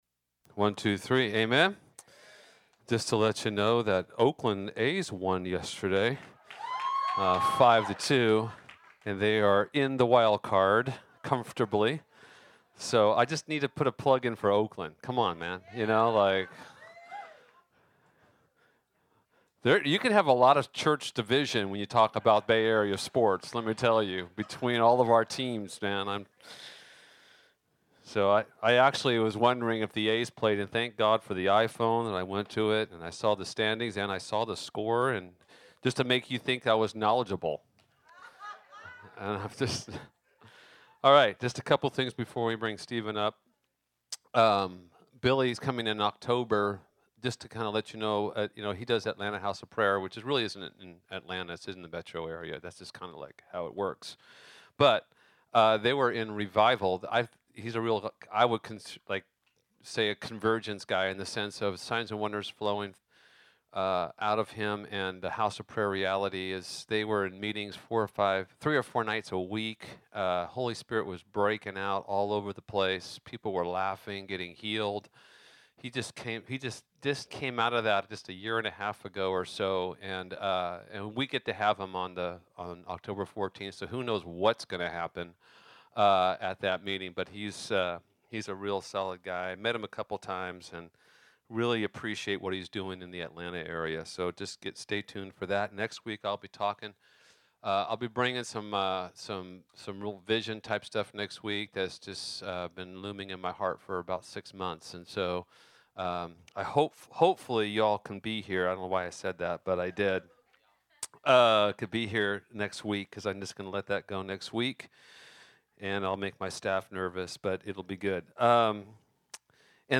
Beginning with the week leading up to the Cross, this sermon peers through biblical windows into the heart of Jesus to behold how He feels about His return. In the end, to love Jesus is to be one who loves His appearing (2 Tim. 4:8). Spoken on September 16, 2012 at Convergence House of Prayer in Fremont, CA. This is the final message in the series on Passion for Jesus from the event that weekend.